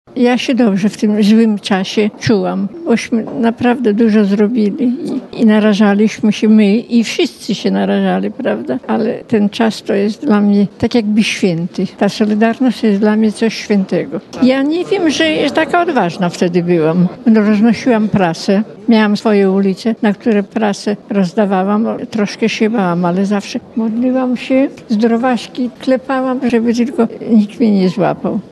Przyznano je podczas uroczystej sesji Rady Miasta w Białej Podlaskiej.
Uroczystą sesję Rady Miasta zorganizowano z okazji Dnia Patrona Białej Podlaskiej św. Michała Archanioła.